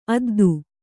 ♪ addu